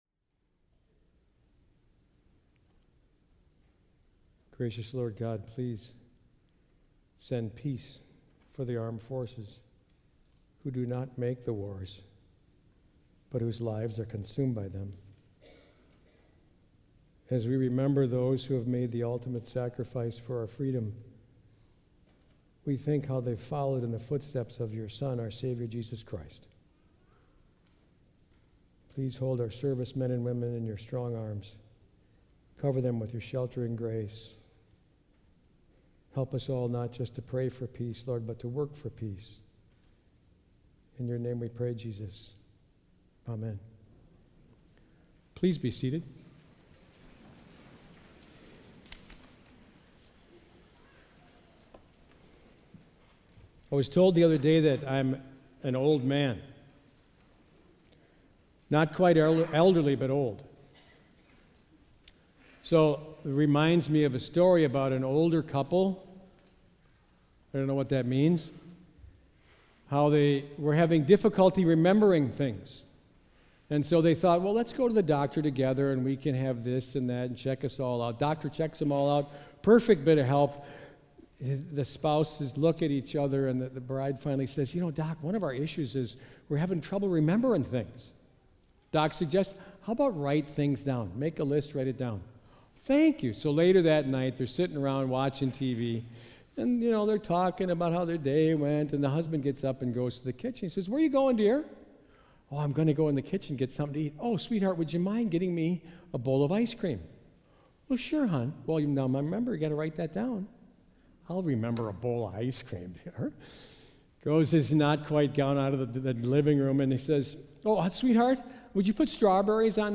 May-26-Sermon-MPLS.mp3